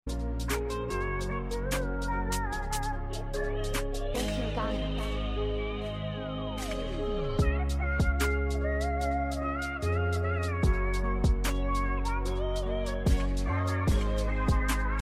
DRILL VER